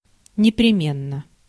Ääntäminen
IPA: /nʲɪprʲɪˈmʲenːə/